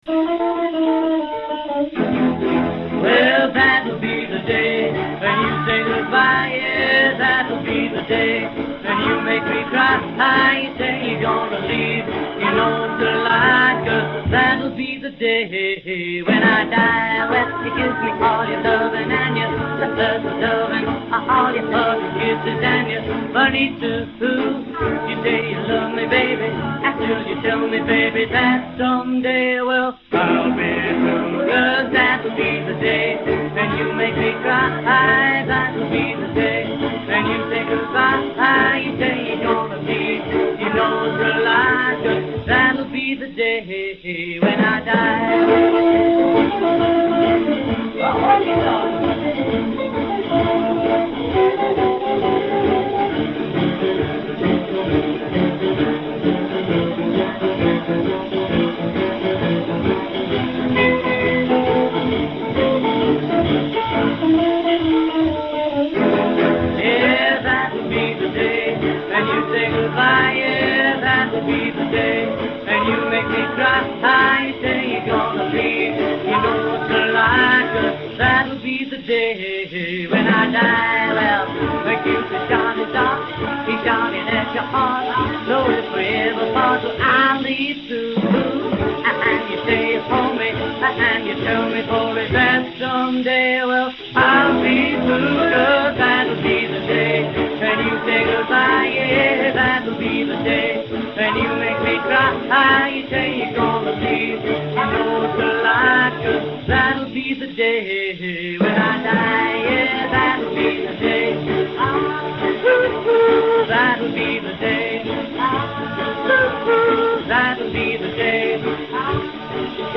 Intro 0:00 2 Guitar: triplets
A Chorus 0:03 8 harmonized vocal repeating hook a
B Verse 0:18 8 solo lead voice with second voice sustain b
C Solo 0:45 12 Guitar solo: Twelve-bar blues format
B Verse 1:22 8 as in verse above (with stops) c
A Chorus 1:50 8 repeat hook, drop intermediate material a'